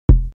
nt kick 7.wav